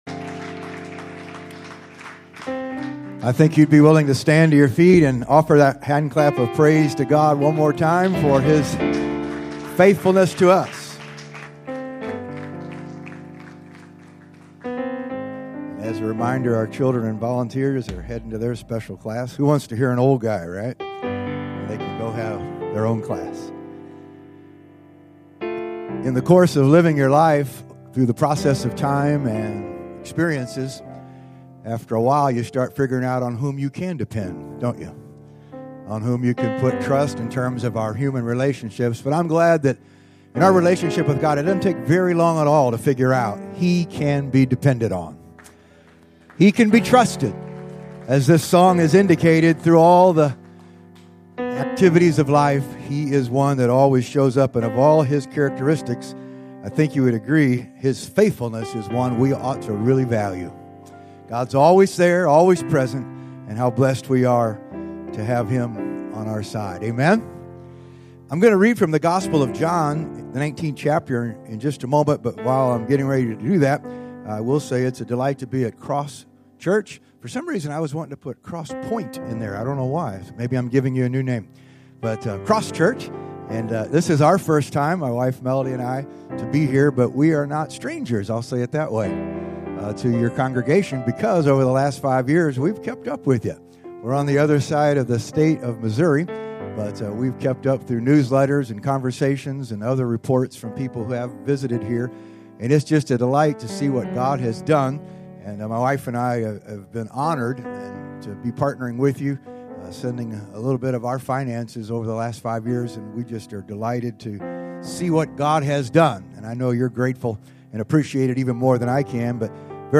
Sermons | Cross Church Kansas City
Guest Speaker